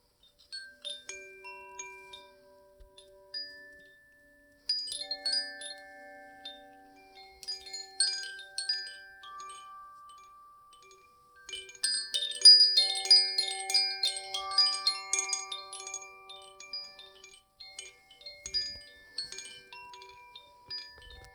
Koshi Wind chime / Musical Instrument. Ignis - (Fire)
Precise tuning creates a play of clear tones that is rich in overtones. The overtones of the shorter chords gradually dominate and become fundamentals, thus forming a circular tone range.
Move the chime gently holding it by its cord: the crystalline relaxing sound may leave you in quiet wonder.
Each has a specific magic timbre and can be played harmoniously with all the others.
Koshi-Wind-Chime-Ignis.wav